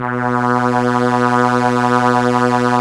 Index of /90_sSampleCDs/Roland LCDP09 Keys of the 60s and 70s 1/STR_ARP Strings/STR_ARP Ensemble